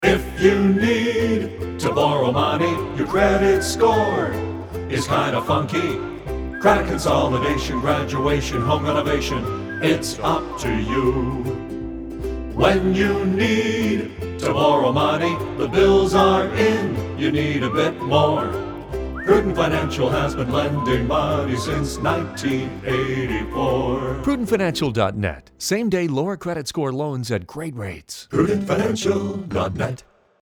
• We dare you to get this song out of your head!